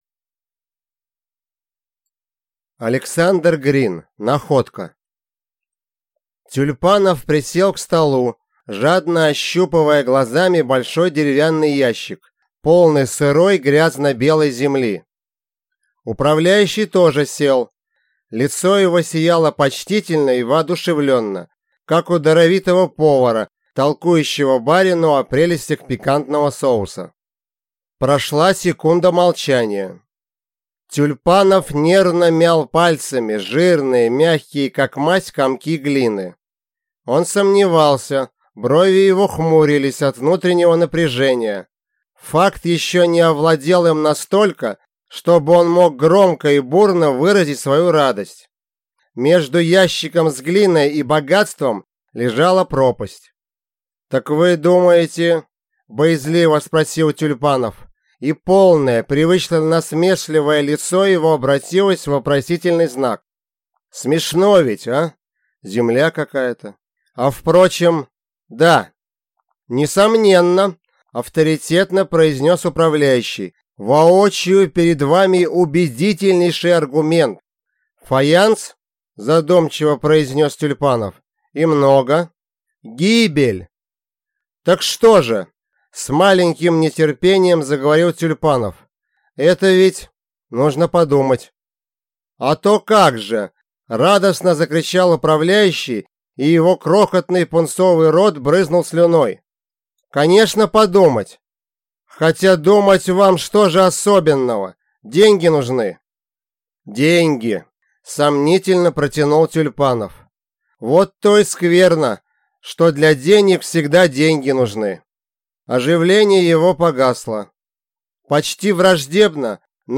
Аудиокнига Находка | Библиотека аудиокниг